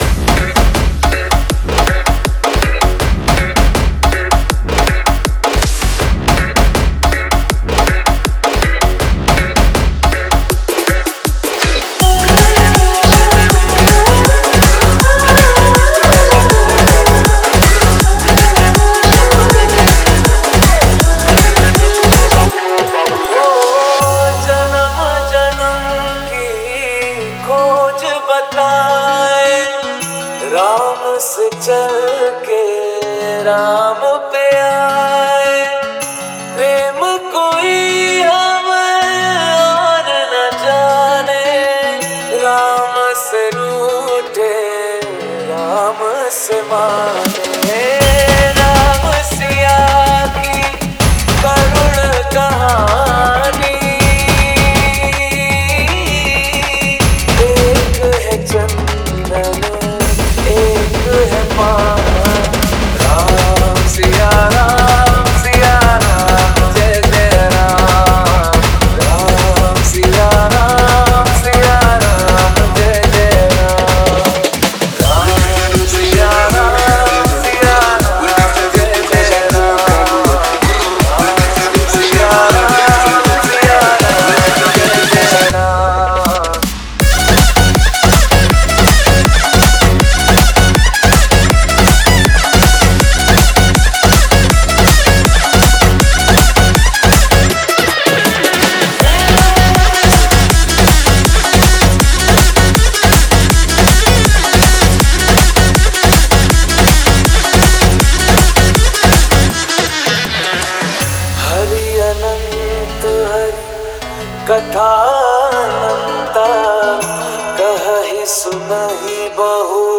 Category: Bollywood Dj Remix Songs